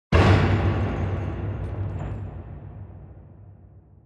impact-2.mp3